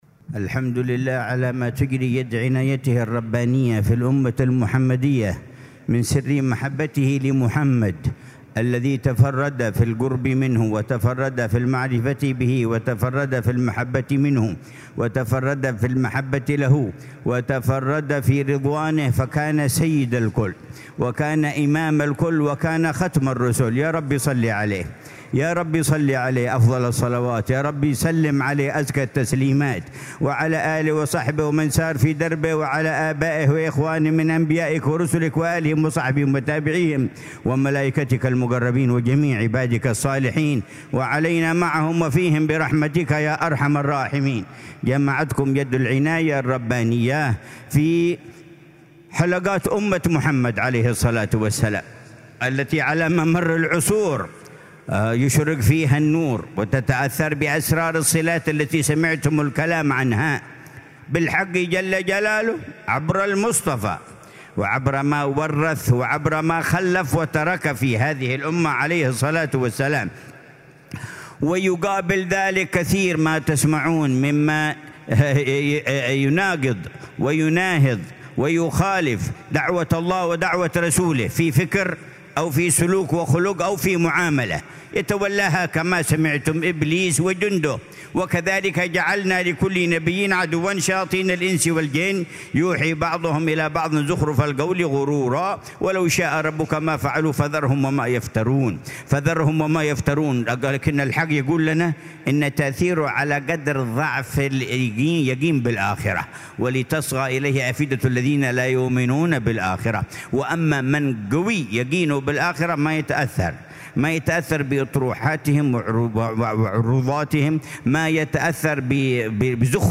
كلمة العلامة الحبيب عمر بن محمد بن حفيظ في الاحتفال بالذكرى التاسعة والعشرين - 29 - لافتتاح دار المصطفى بتريم للدراسات الإسلامية، ظهر يوم الأربعاء 29 ذو الحجة 1446هـ